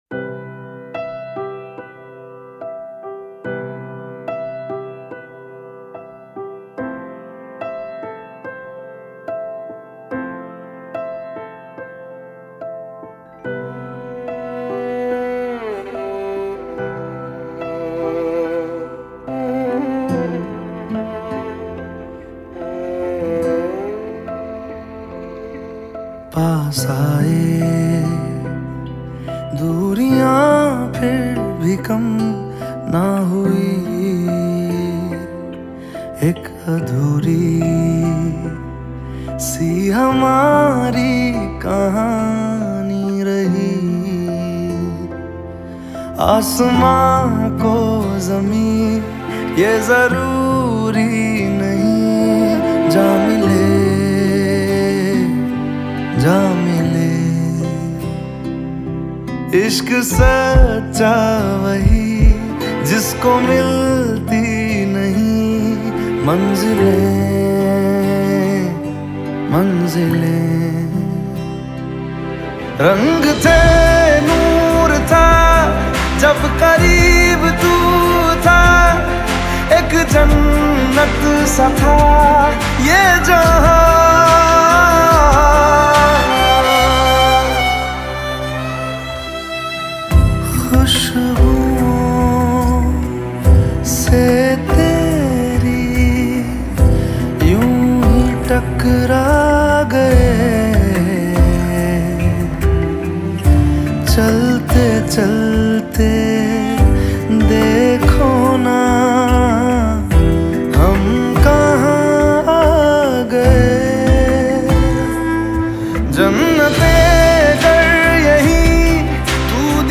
Lyrical Robotic Remix Mp3 Song